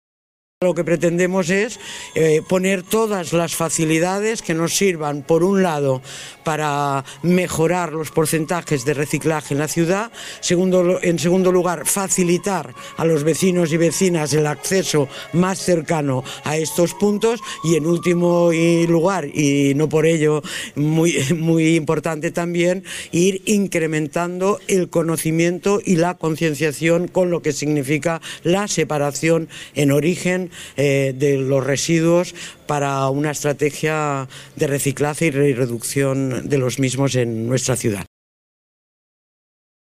Inés Sabanés explica la finalidad de estos puntos limpios